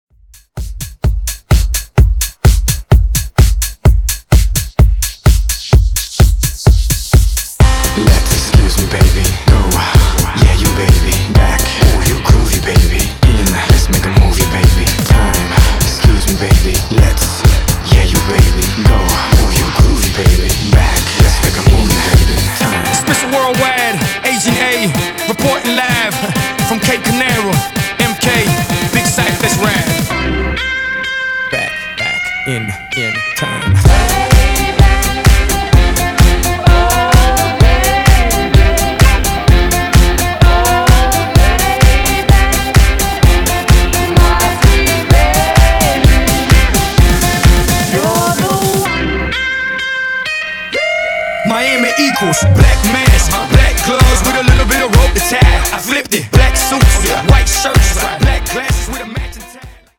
Genres: 90's , RE-DRUM
Clean BPM: 95 Time